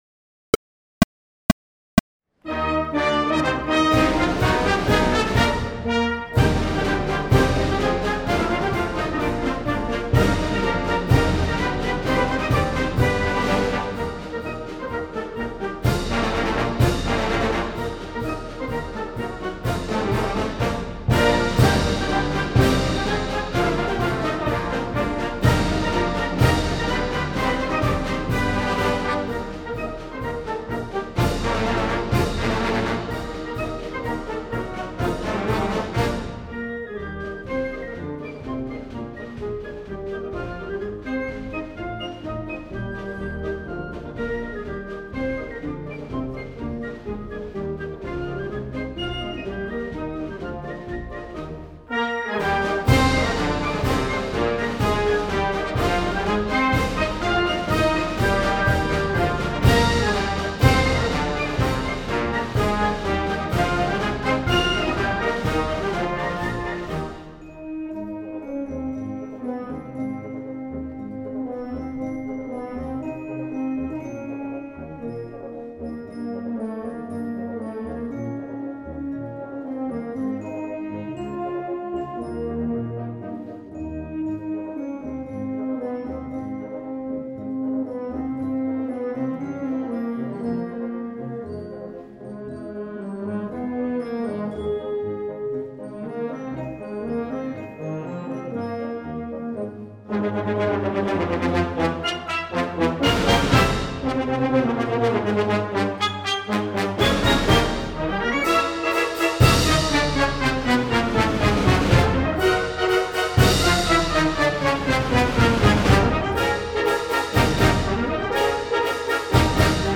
- Follow the recording, starting after the four prep clicks.
Reference Recording (click track): The Stars and Stripes Forever by John Philip Sousa